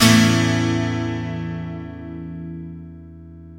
GTR EL-AC 0B.wav